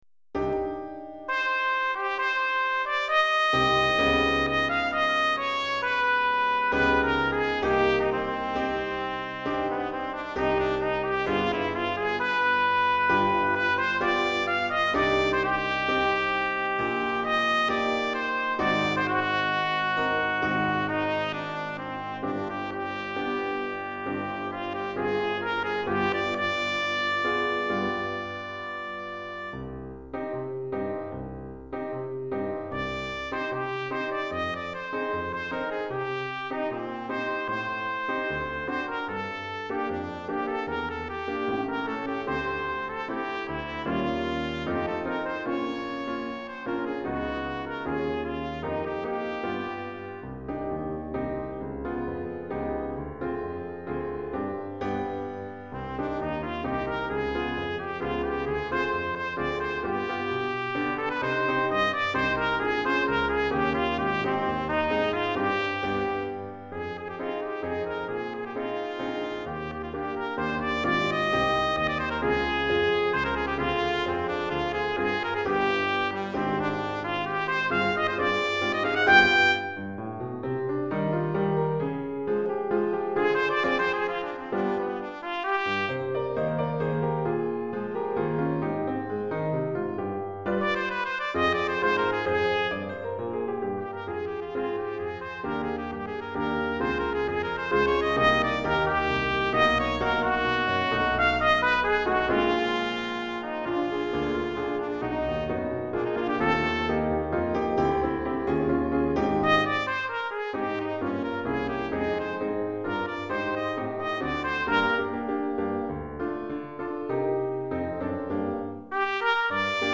Répertoire pour Trompette ou cornet